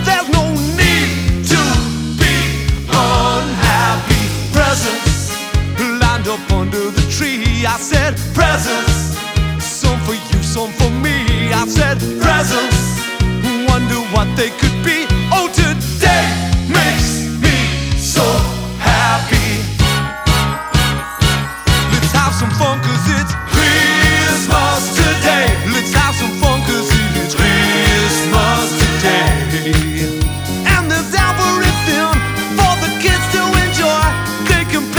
# Comedy